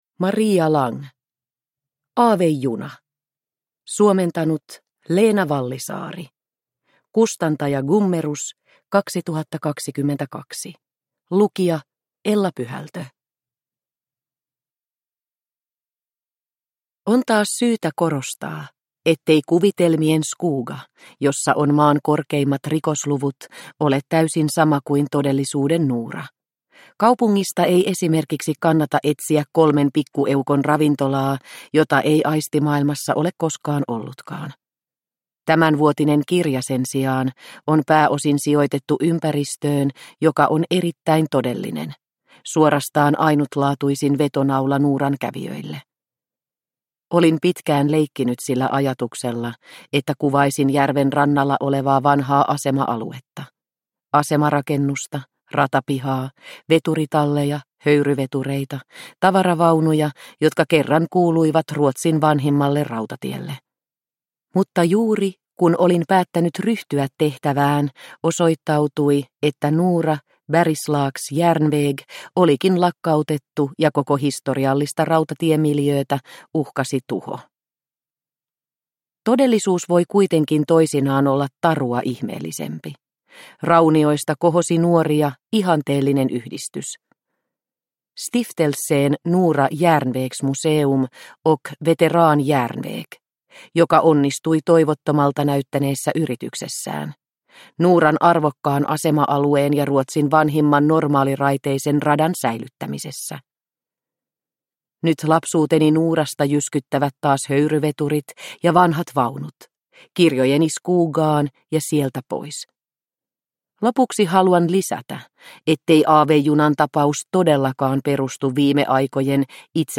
Aavejuna – Ljudbok – Laddas ner